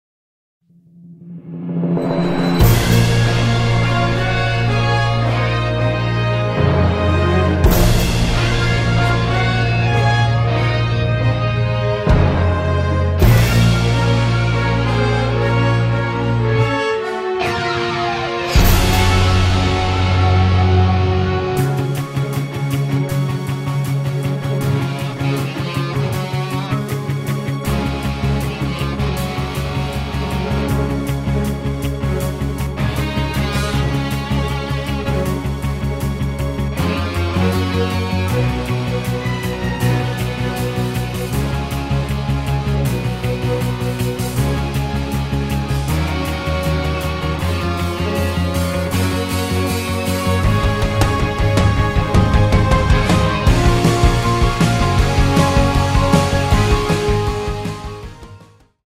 performance track
backing track
Instrumental , orchestral